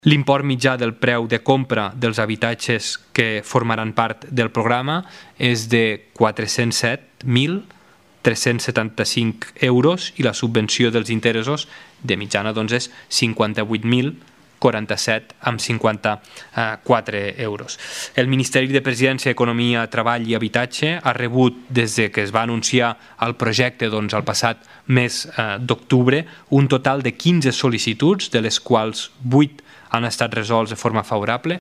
El ministre portaveu, Guillem Casal, ha donat més detalls sobre el programa.